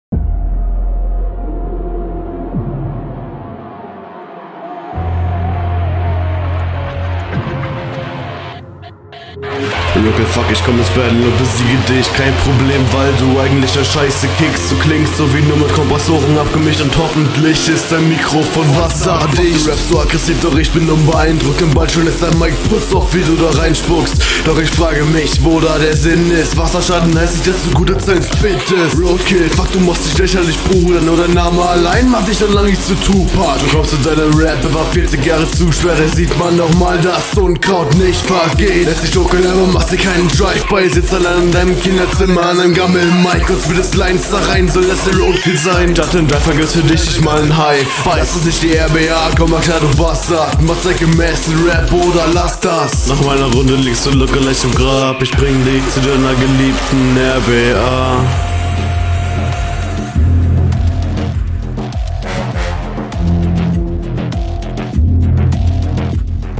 Stabiler Beat, Stimme sitzt hier deutlich angenehmer im Beat; der Einstieg gelingt hier nicht so …
Auf dem Beat kommst du deutlich besser, flow stockt immernoch manchmal und die stimme ist …